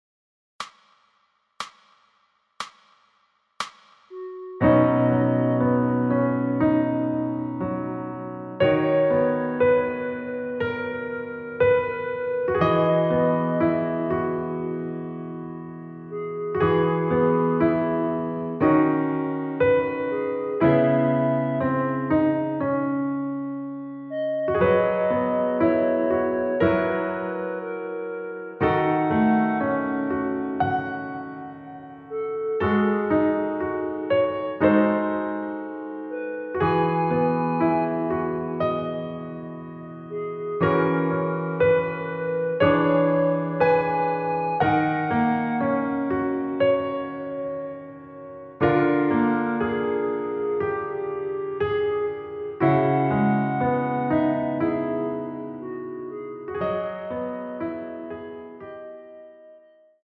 여자키 멜로디 포함된 MR 입니다.(미리듣기 참조)
앞부분30초, 뒷부분30초씩 편집해서 올려 드리고 있습니다.
중간에 음이 끈어지고 다시 나오는 이유는